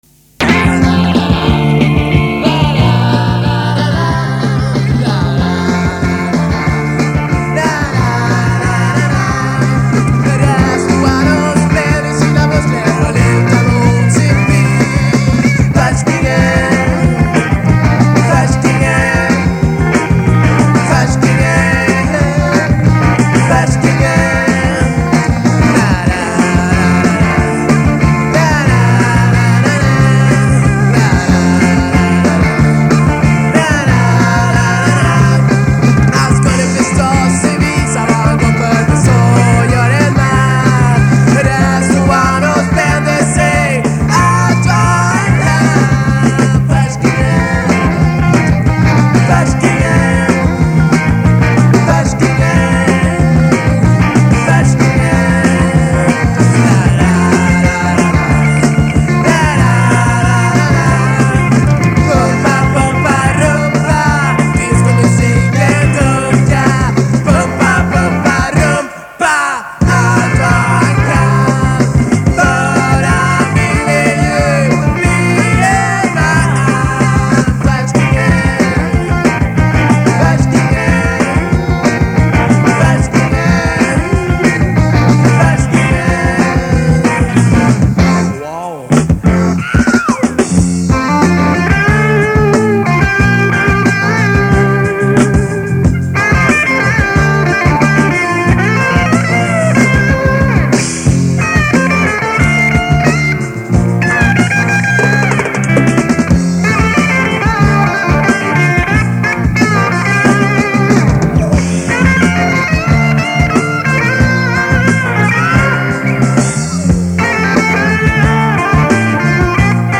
Mycket rare Flexidisc